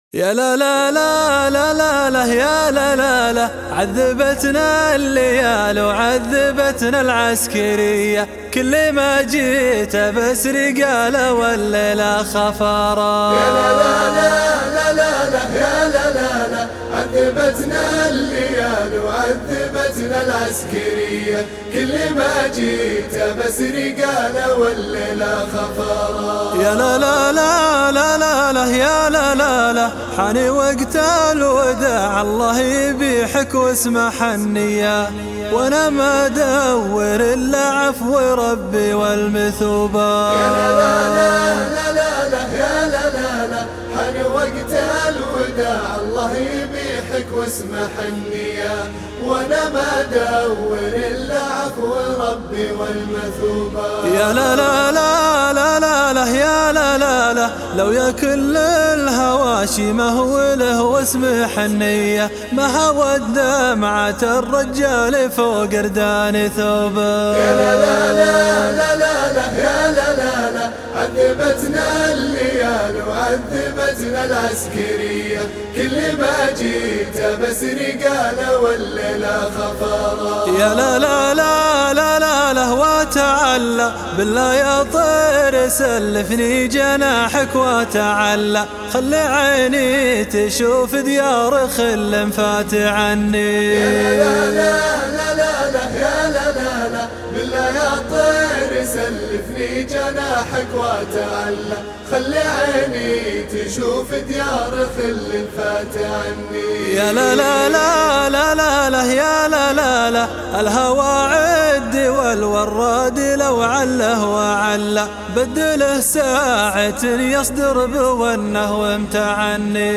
الشيله